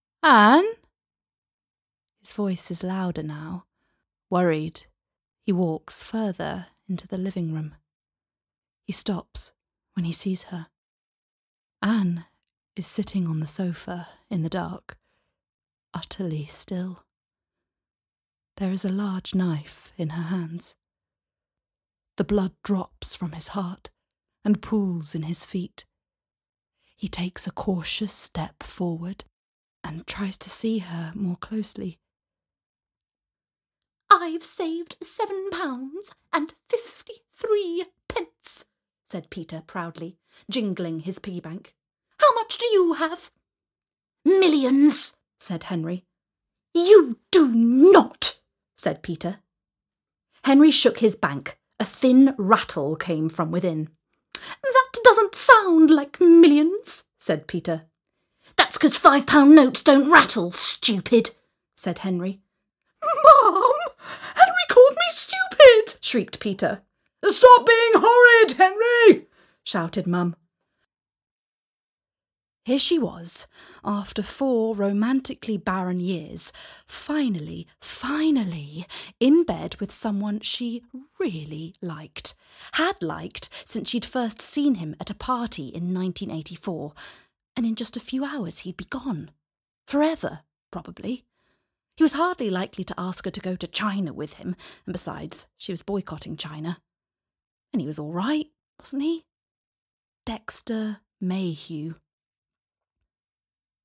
• Native Accent: Bristol, RP
• Home Studio